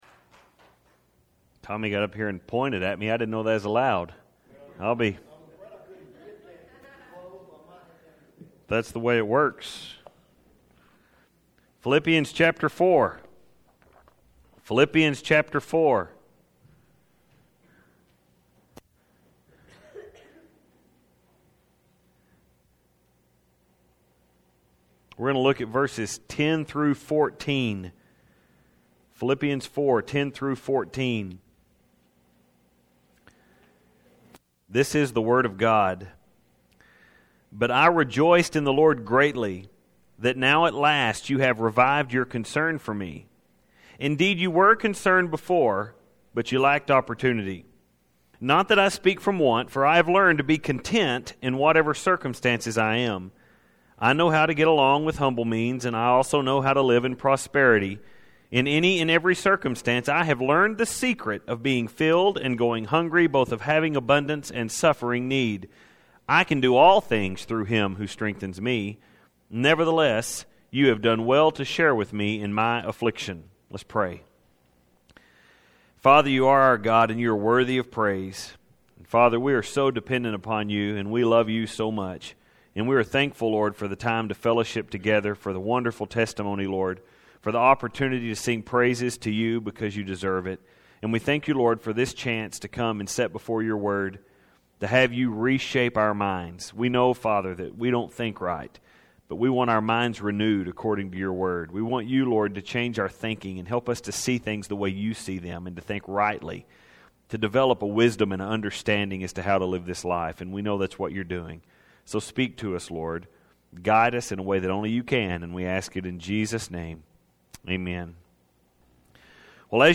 Filed Under: Sermons, Uncategorized